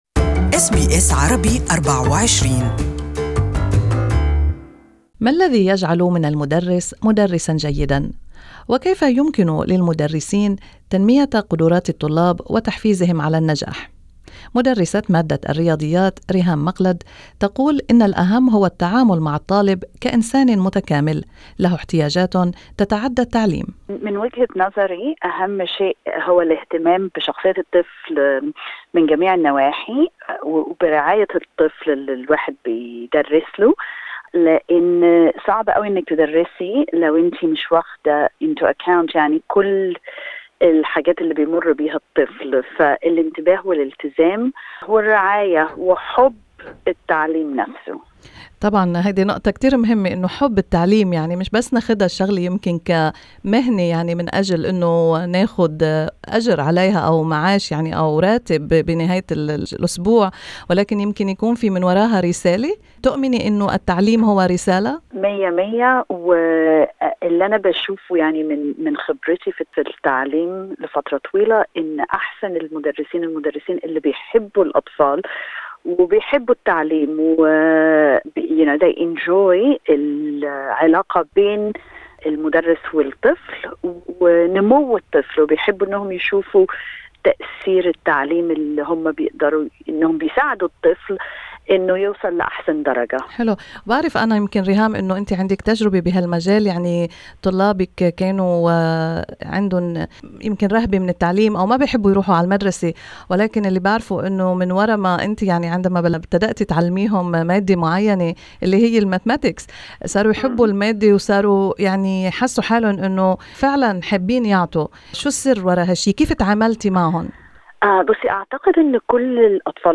In this interview